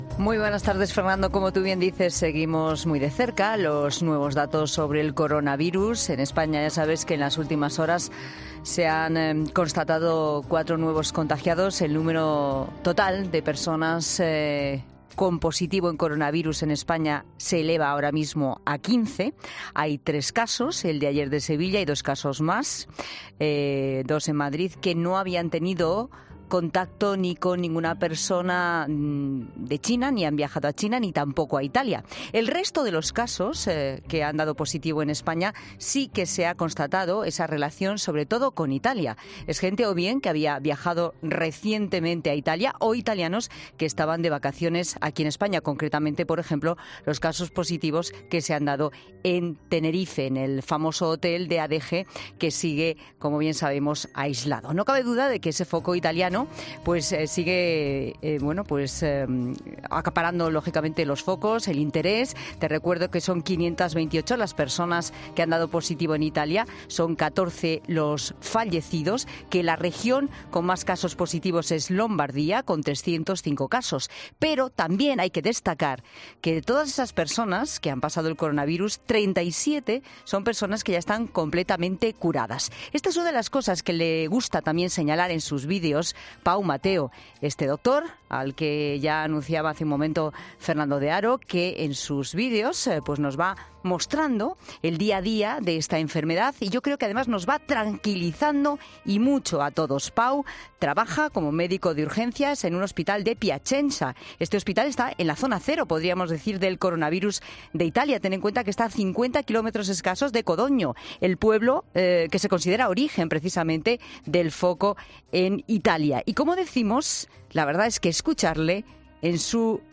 Escucha toda la entrevista a este médico español que trabaja día y noche en uno de los principales focos de esta enfermedad en Italia.